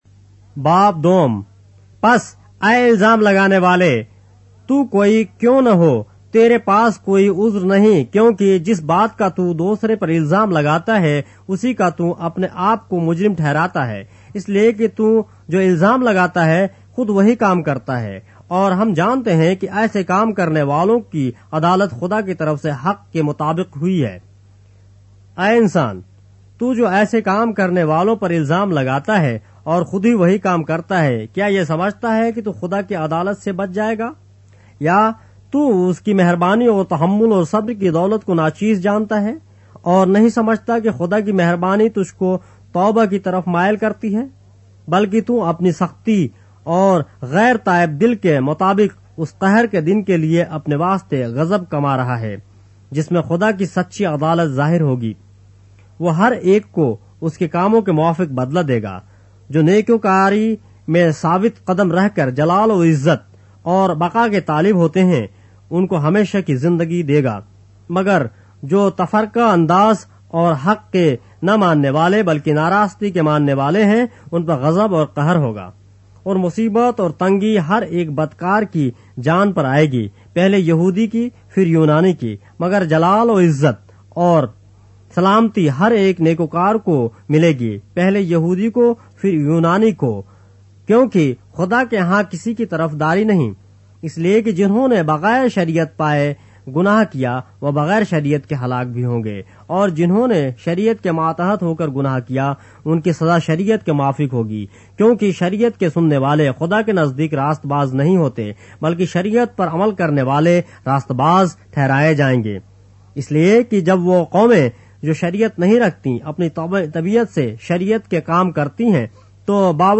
اردو بائبل کے باب - آڈیو روایت کے ساتھ - Romans, chapter 2 of the Holy Bible in Urdu